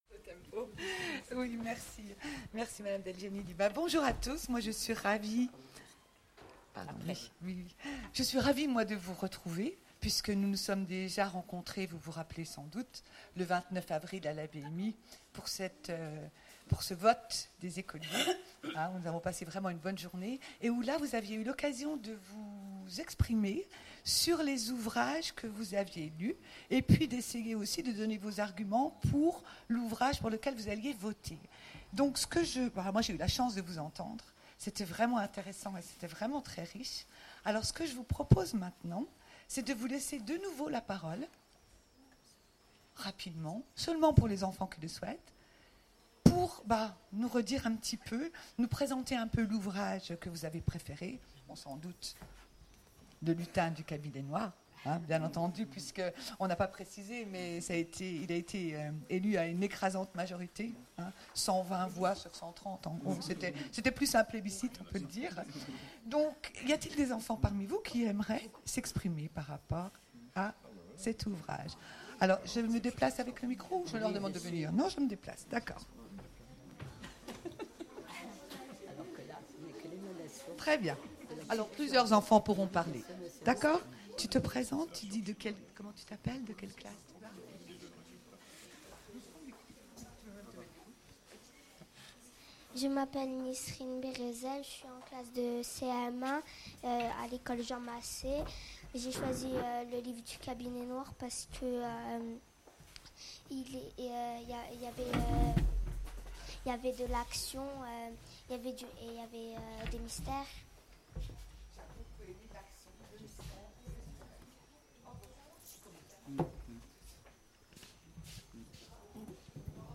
Mots-clés Remise de prix Conférence Partager cet article